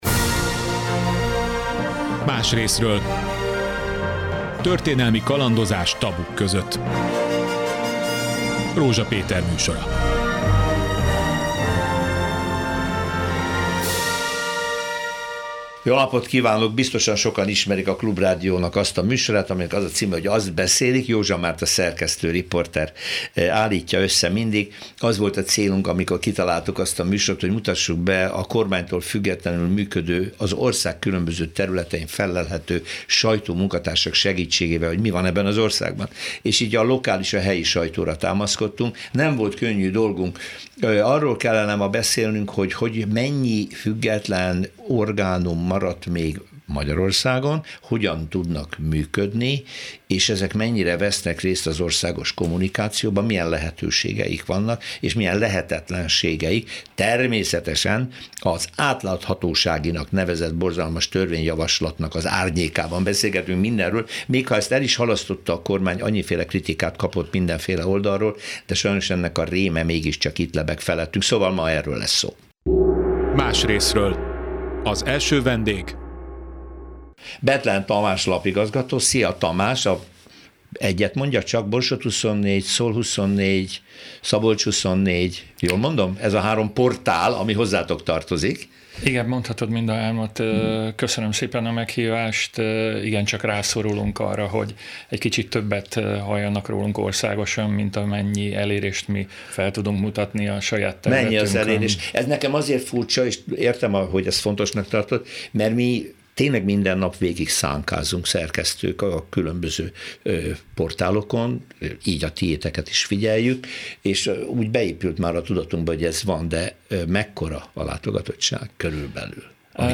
A vidéki, szabad sajtó túlélési esélyeiről beszélgettek az érintettek a Klubrádióban